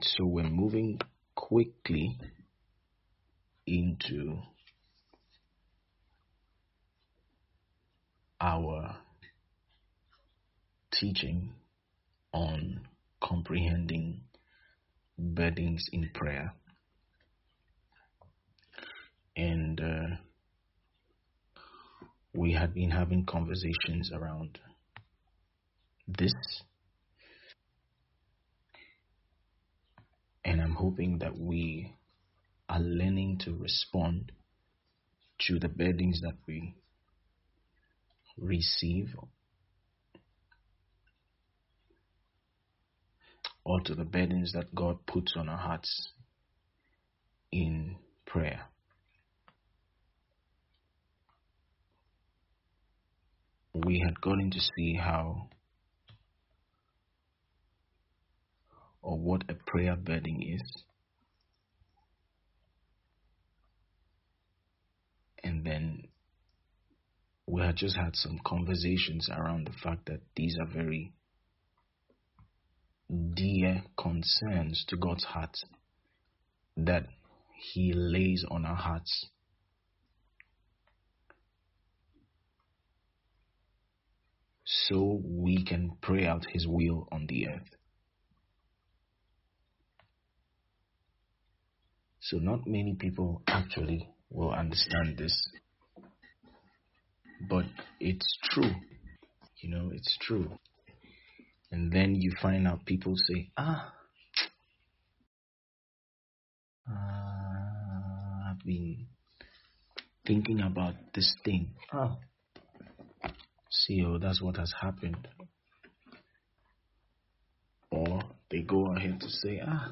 Understanding Burdens in Prayer is a 2-part sermon series